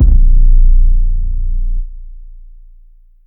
808 (Pinocchio).wav